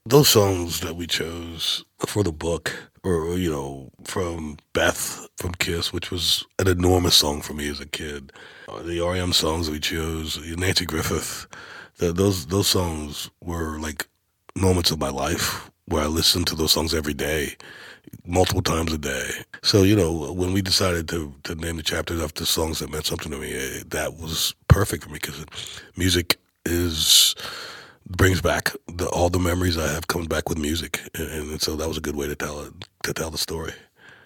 Darius Rucker talks about the songs and artists that influenced his new memoir.